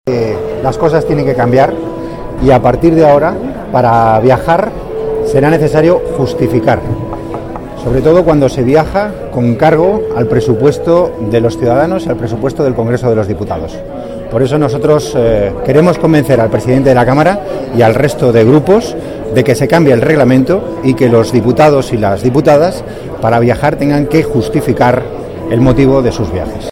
Declaraciones de Antonio Hernando en los pasillos del Congreso sobre la necesidad de justificar los viajes pagados por la Cámara a los diputados 13/11/2014